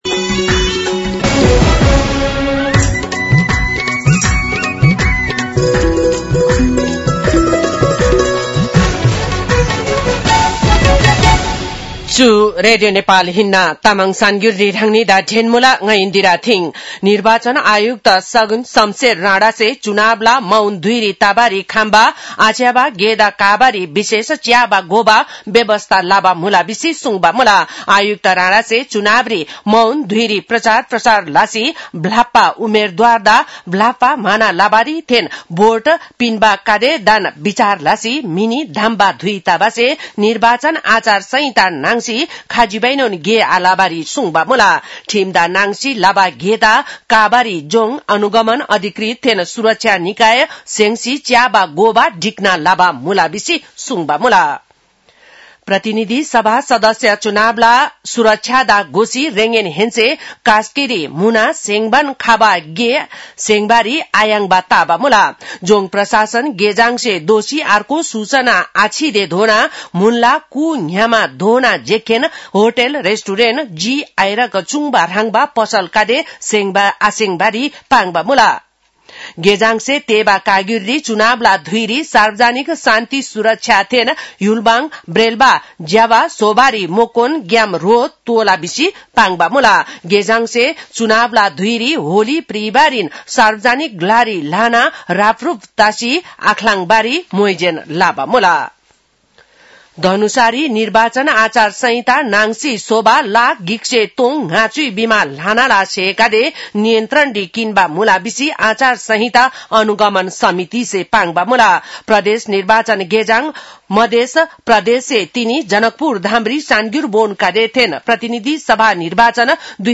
तामाङ भाषाको समाचार : १६ फागुन , २०८२